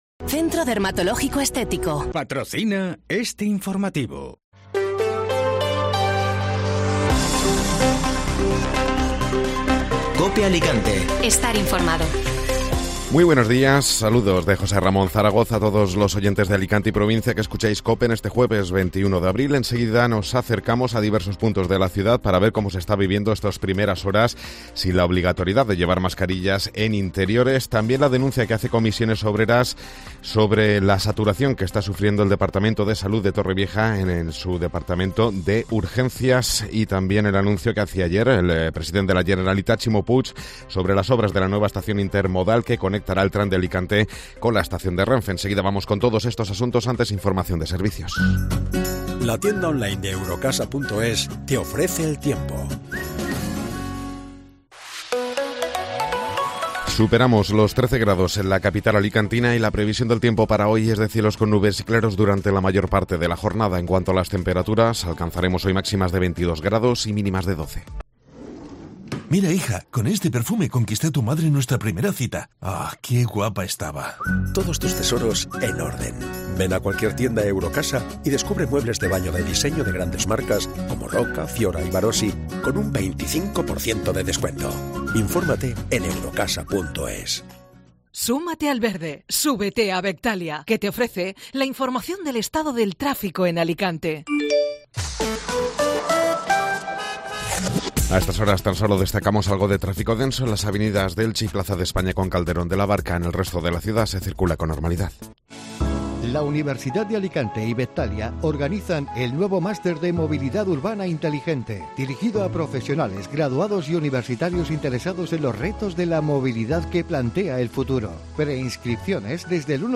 Informativo Matinal (Jueves 21 de Abril)